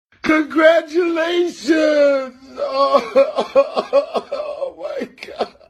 tyrese congratulations Meme Sound Effect